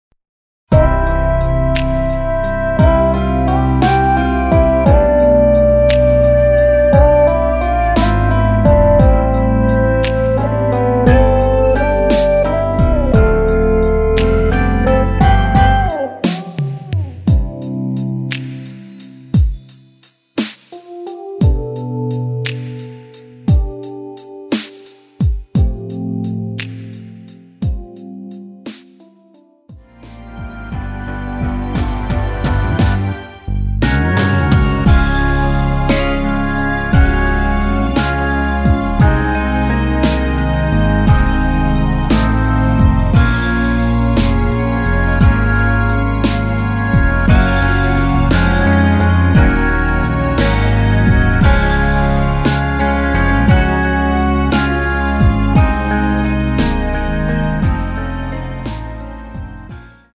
엔딩이 페이드 아웃이라 엔딩을 만들어 놓았습니다.